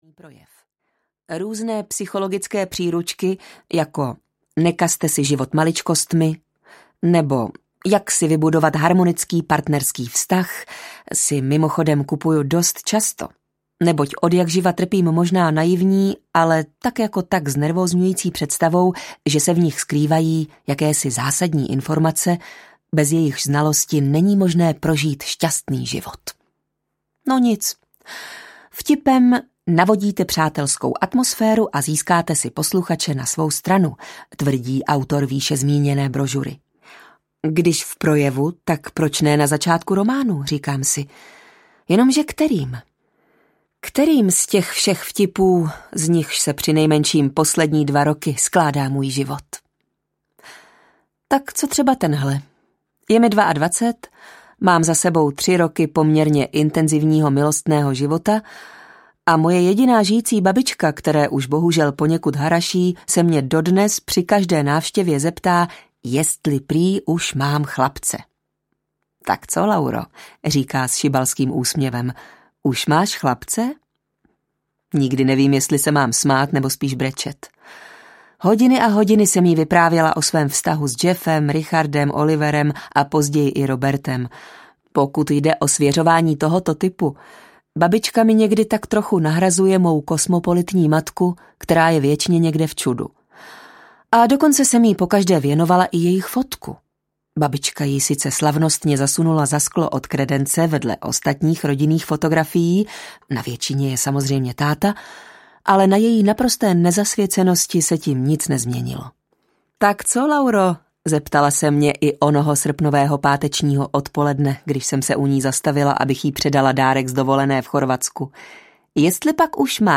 Román pro ženy audiokniha
Ukázka z knihy